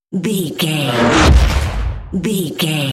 Sci fi whoosh to hit fast
Sound Effects
Fast
futuristic
intense
woosh to hit